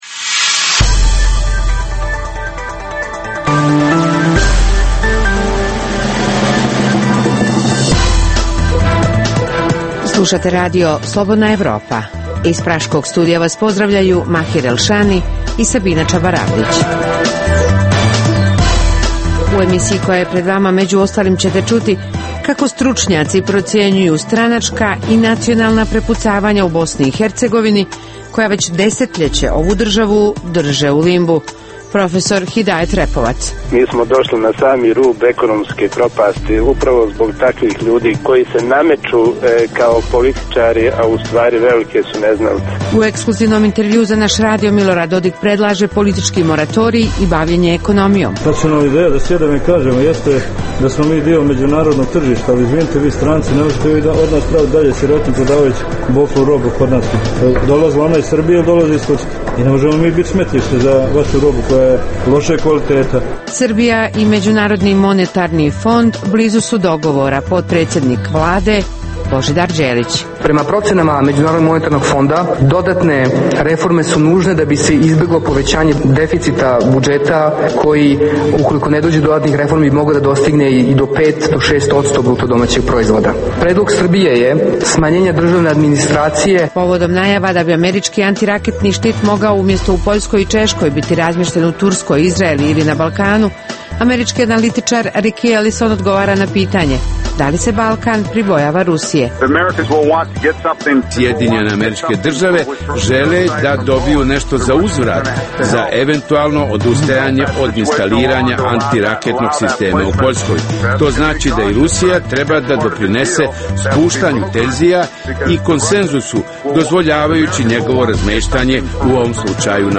U emisiji koja je pred vama čućete zašto SDA i HDZ prijete izlaskom iz državne, odnosno entitetske vlasti u BiH, interview s premijerom RS Miloradom Dodikom u kojem on, prvi put, iznosi u javnost ideju o zamrzavanju političkih i intenziviranju ekonomskih problema u Bosni i Hercegovini, te odgovor na pitanje u kojoj mjeri će dogovor EULEX-a i srbijanskog MUP-a imati efekta nakon što je kosovska Vlada sporazum odbila.